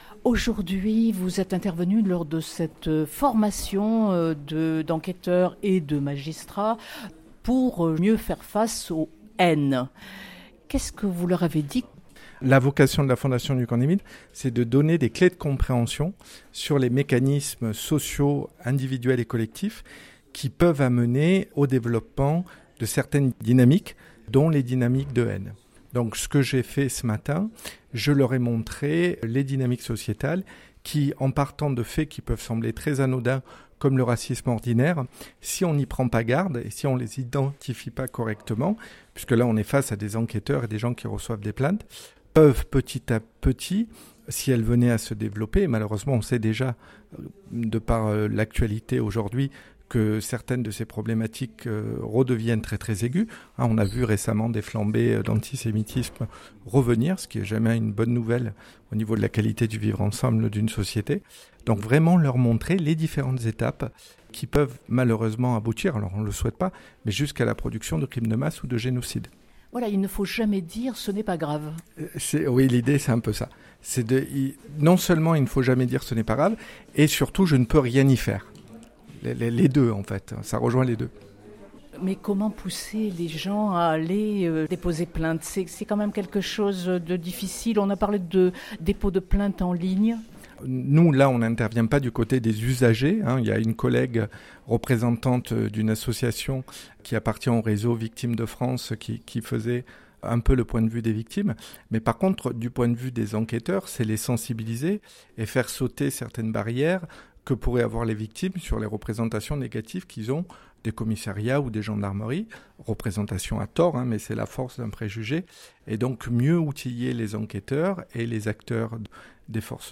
Une journée de formation -organisée par la Dilcrah et co-pilotée avec les ministères de l’Intérieur et de la Justice, en partenariat avec le Camp des Milles- s’est tenue le 1er juillet en Préfecture de région Provence-Alpes-Côte d’Azur à Marseille.